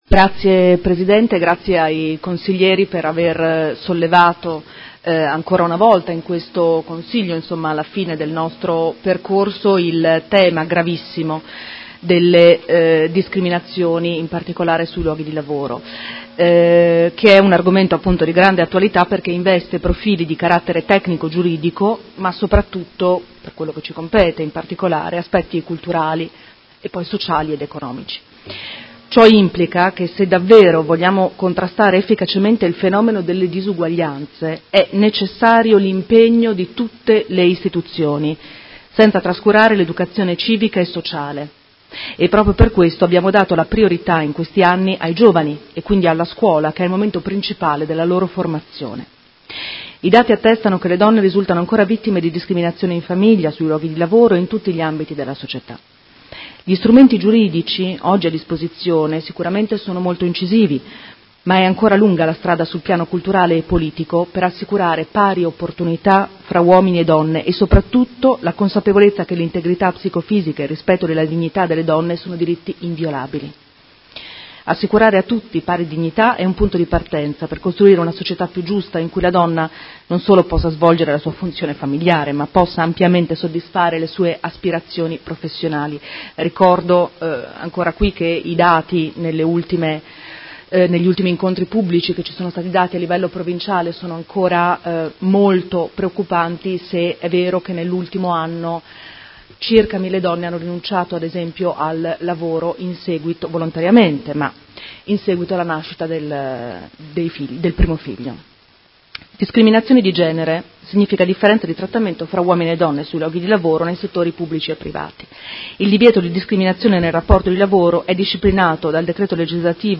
Seduta del 04/04/2019 Risponde. Interrogazione dei Consiglieri Malferrari, Cugusi e Stella (SUM) avente per oggetto: Diritti negati e molestie sessuali nei luoghi di lavoro a Modena e Provincia a danno delle donne